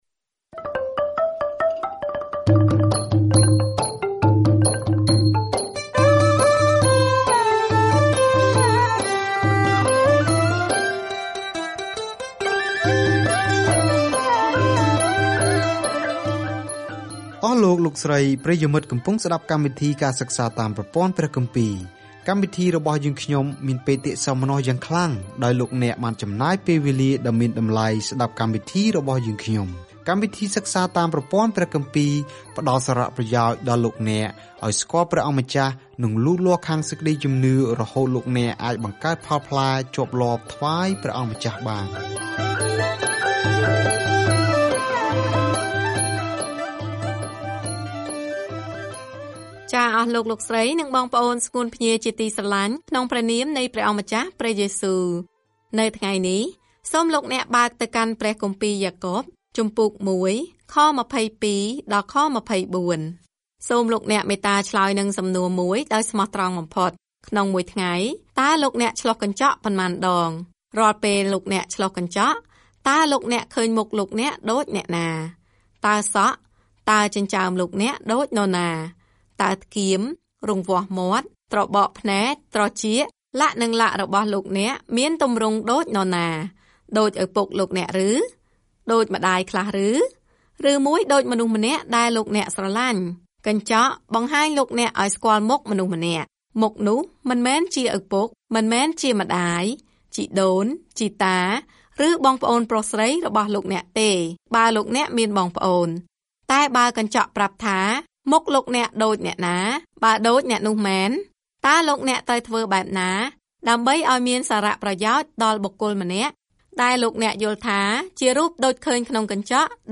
ការធ្វើដំណើរជារៀងរាល់ថ្ងៃតាមរយៈយ៉ាកុប នៅពេលអ្នកស្តាប់ការសិក្សាជាសំឡេង ហើយអានខគម្ពីរដែលជ្រើសរើសពីព្រះបន្ទូលរបស់ព្រះ។